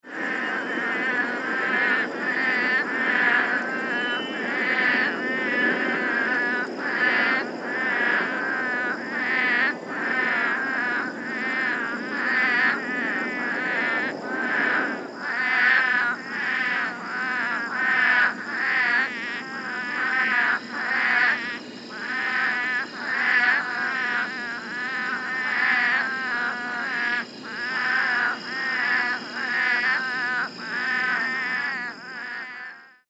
The call of Couch's Spadefoot is very loud nasal groan descending in pitch similar to a lamb bleating.
Sound This is a 33 second recording of a group of spadefoots calling from a flooded wash at night in Pima County, Arizona (shown on the right.)